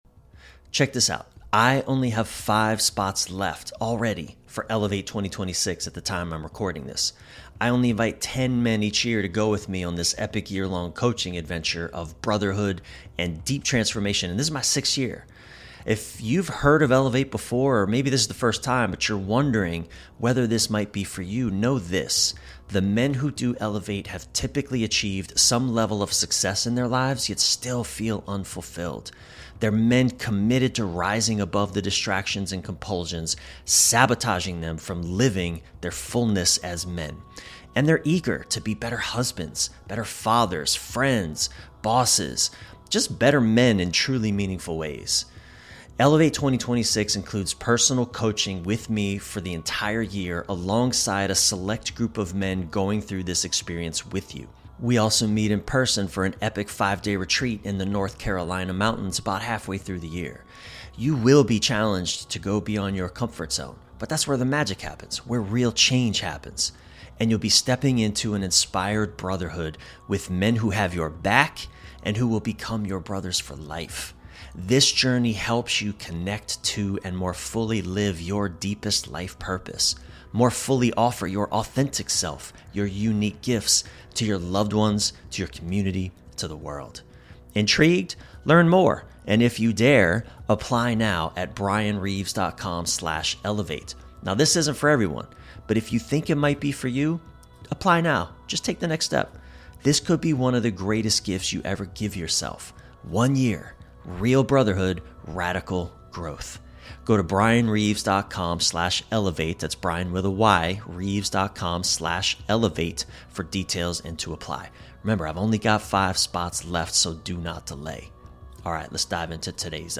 This is a must-listen conversation for anyone who wants to understand why men struggle with intimacy—and how they can finally come home to their hearts.